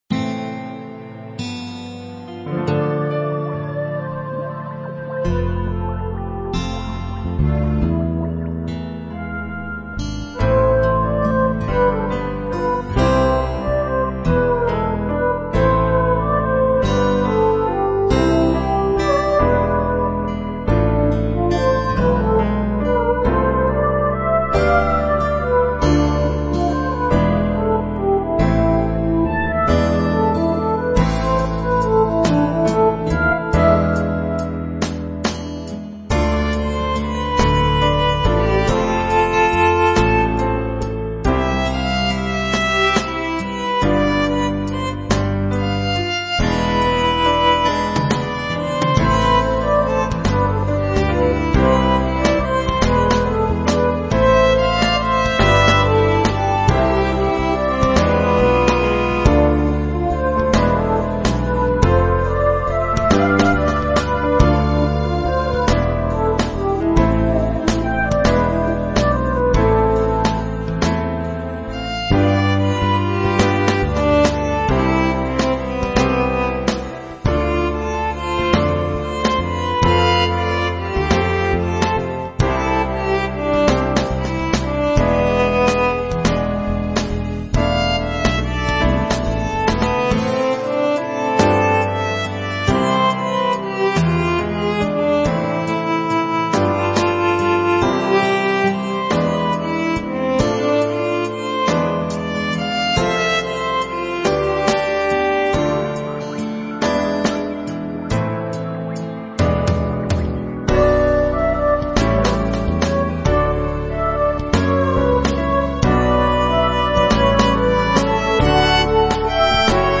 Dramatic Soundtrack Music with Deep Sentimental feel